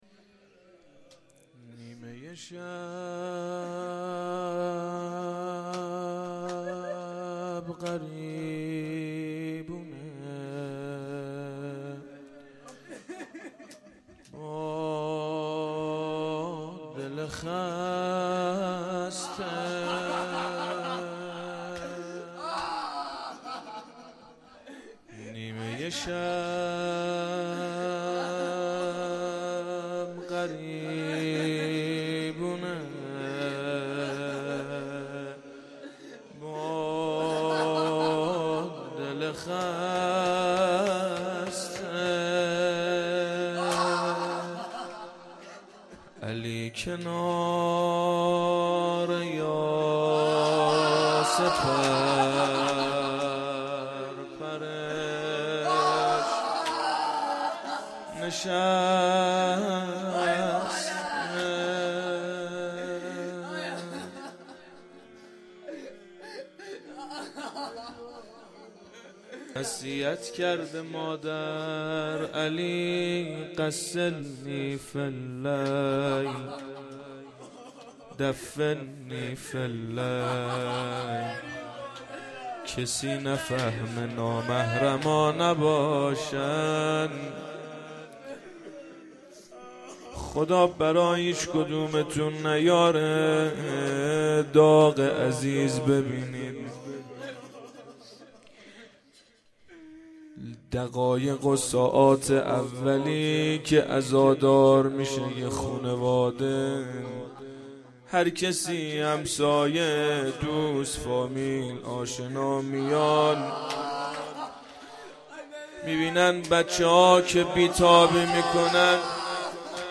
roze.mp3